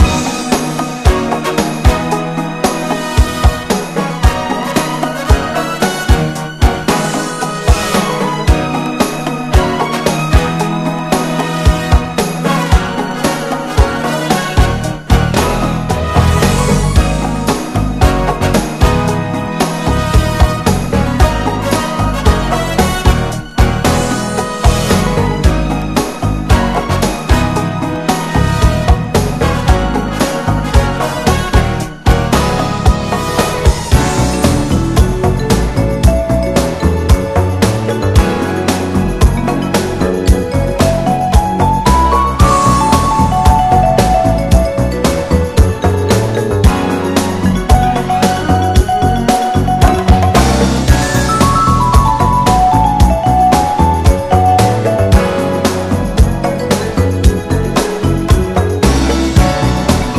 BREAKBEATS/HOUSE / ACID JAZZ / UK SOUL / GROUND BEAT (UK)
洗練のアーバン・ソウル
UKクラブ〜ACID JAZZシーン直系のサウンドを全編で展開。